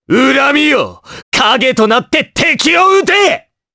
Dimael-Vox_Skill5_jp.wav